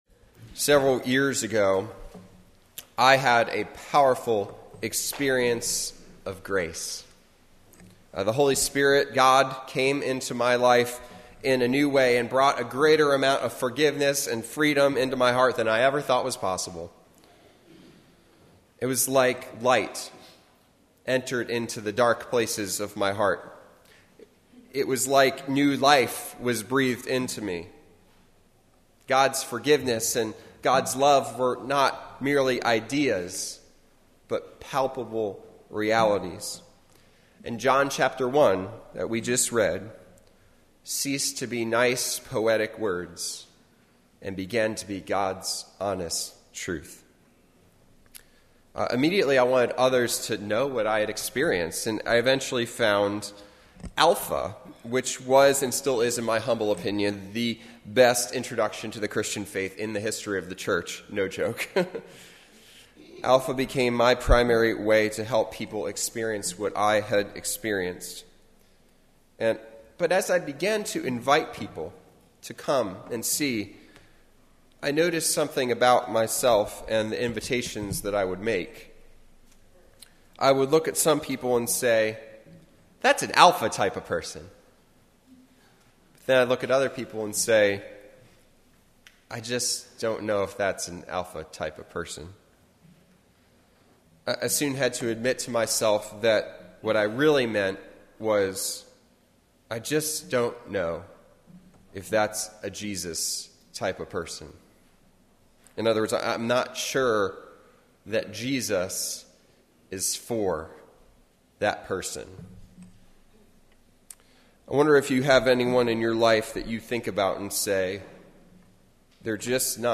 A Sermon for Epiphany Sunday on John 1:1-18